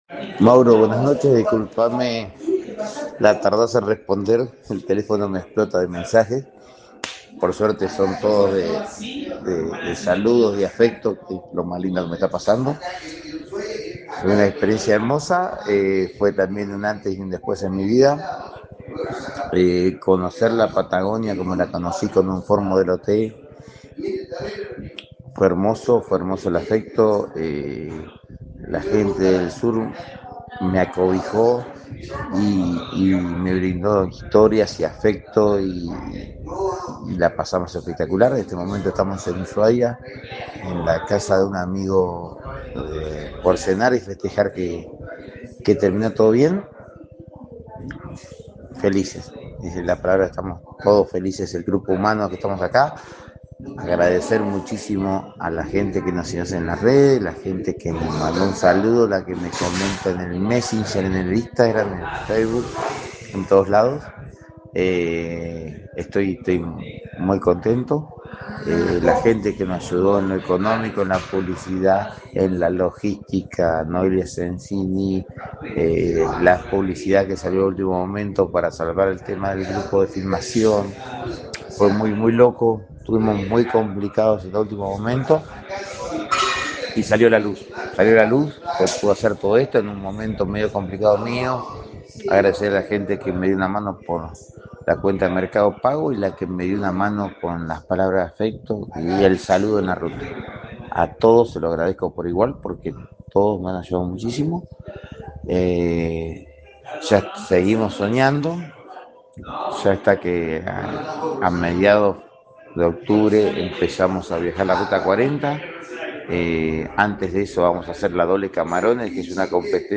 La experiencia como relató a la zfm, marcó “un antes y un después” en su vida y estuvo cargada de historias, afecto y acompañamiento de la gente durante todo el recorrido.